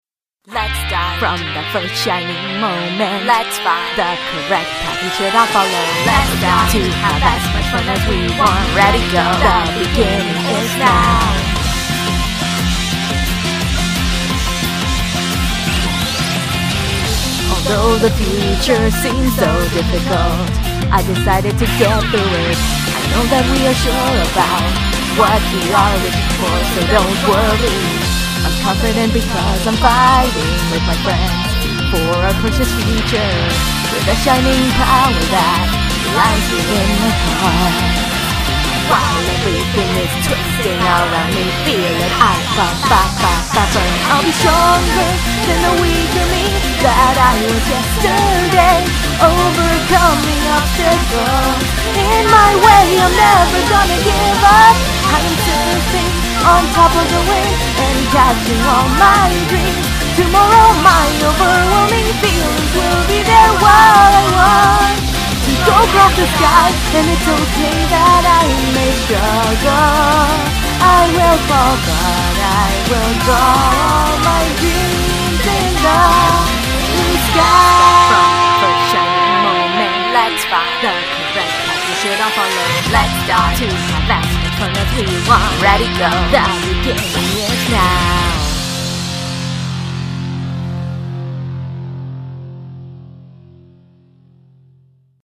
English TV. Version